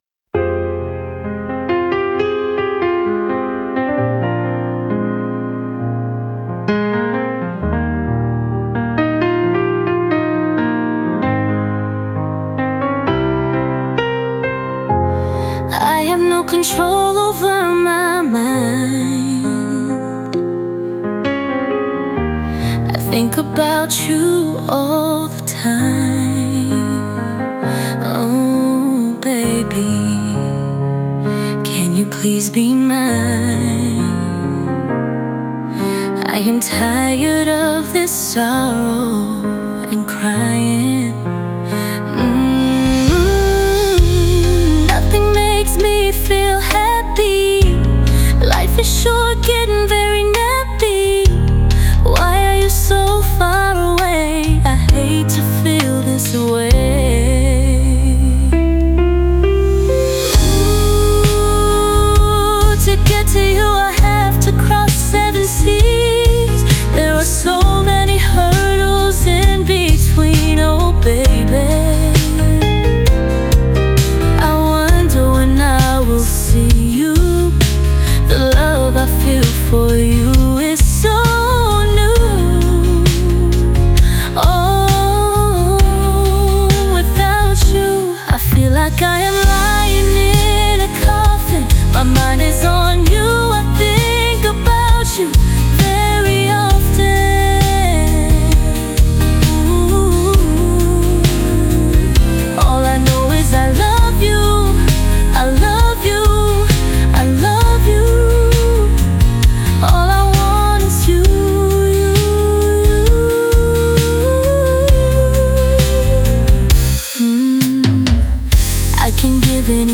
"Across Seven Seas" (pop-R&B)